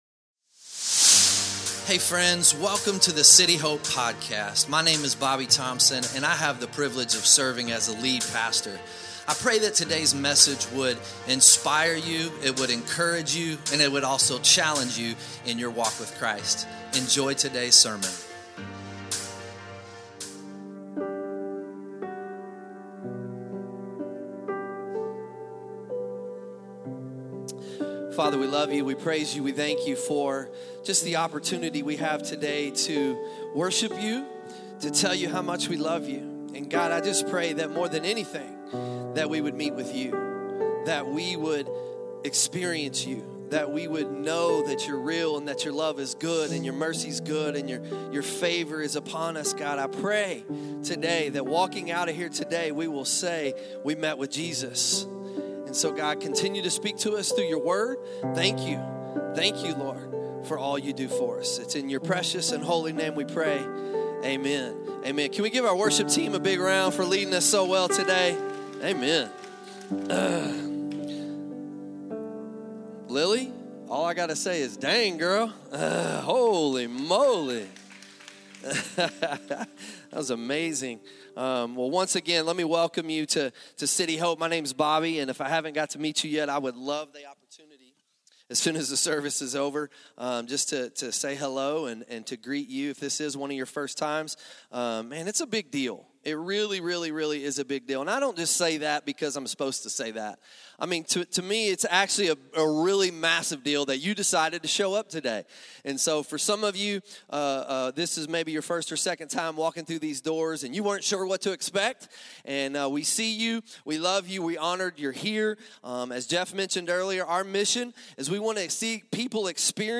2026 Sunday Morning Here is what I think we see here….